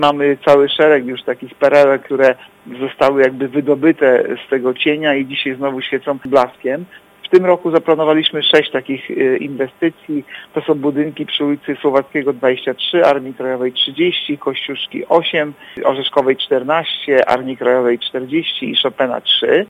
Mówi Artur Urbański zastępca prezydenta Ełku.